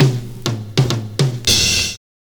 100CYMB13.wav